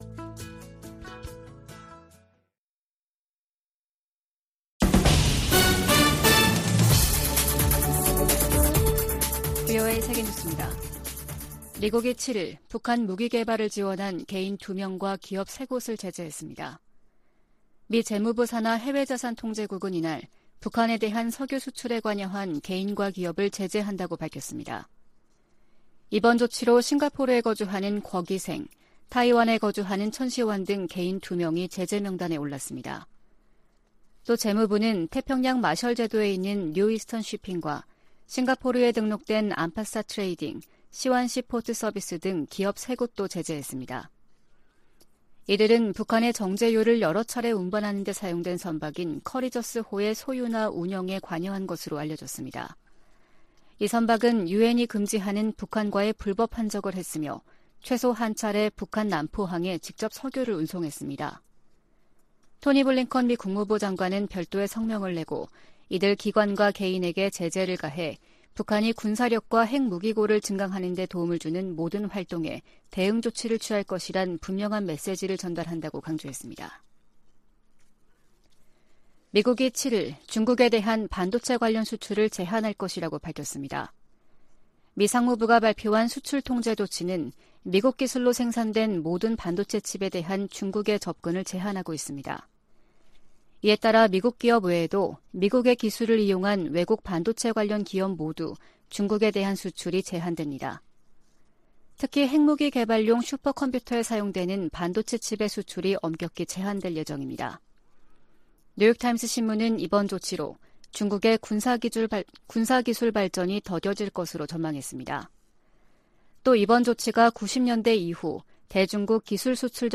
VOA 한국어 아침 뉴스 프로그램 '워싱턴 뉴스 광장' 2022년 10월 8일 방송입니다. 한국 정부는 북한이 7차 핵실험을 감행할 경우 9.19 남북 군사합의 파기를 검토할 수 있다고 밝혔습니다. 미국 정부는 북한의 탄도미사일 발사 등 도발에 대응해 제재를 포함한 여러 방안을 활용할 것이라고 밝혔습니다. 미 국방부는 북한의 최근 탄도미사일 발사에 대해 무책임하고 불안정한 행동을 즉시 중단할 것을 촉구했습니다.